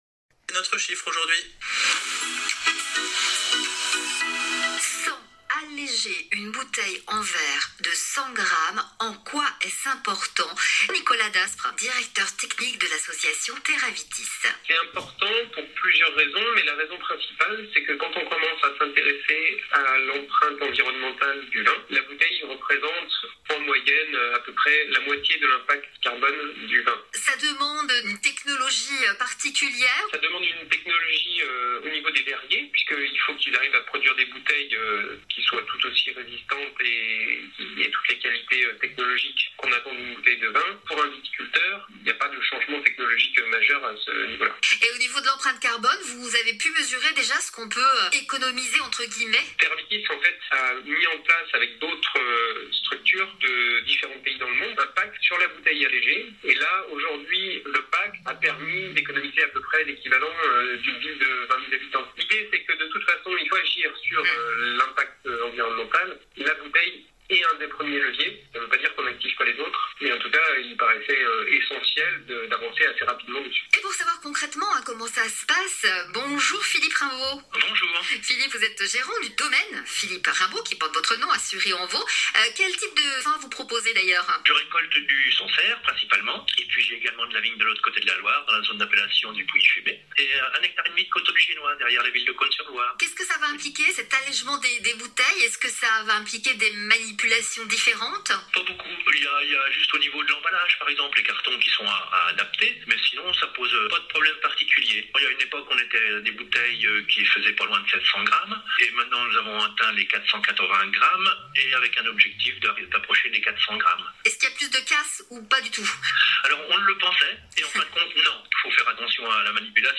Dans cette interview, il évoque notamment un choix concret et fort : la réduction progressive du poids de nos bouteilles. Un geste simple en apparence, mais qui permet de limiter notre impact environnemental, tant sur la consommation de matières premières que sur le transport.